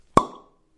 描述：打开酒瓶的软木塞
Tag: 软木 开口